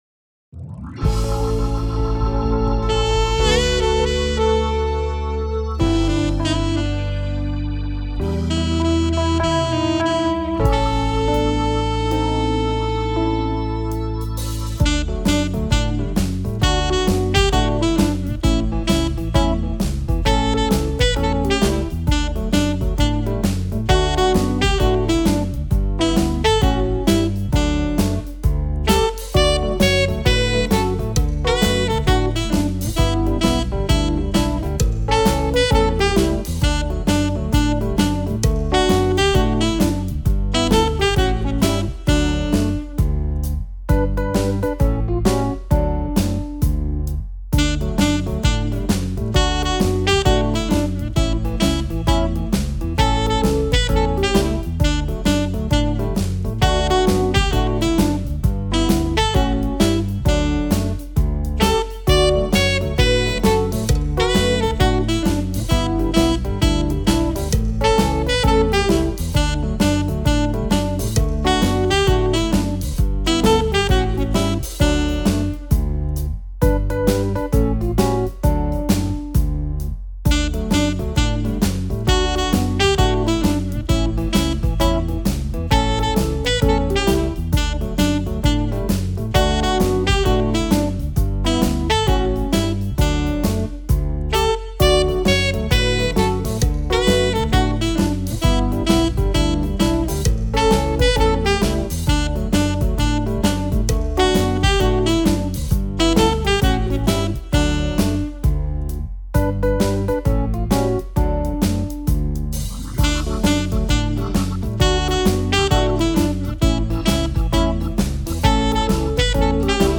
(just instruments) or the choir, please click on the